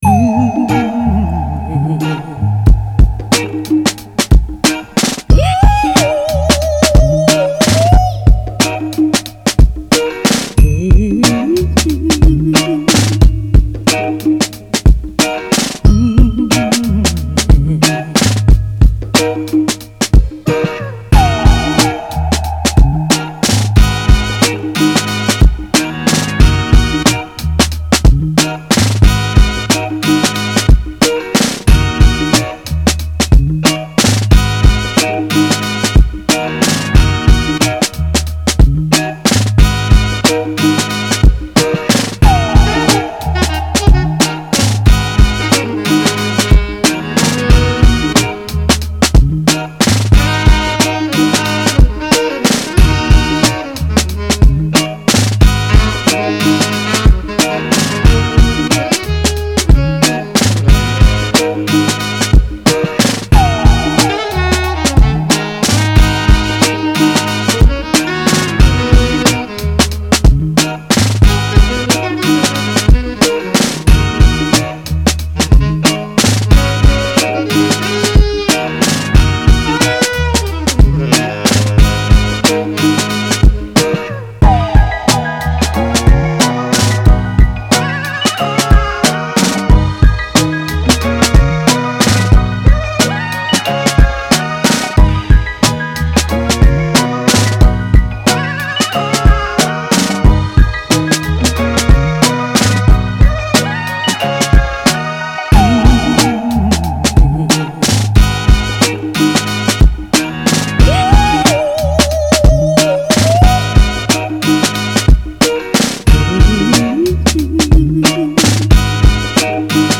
Vintage, Positive, Action, Soul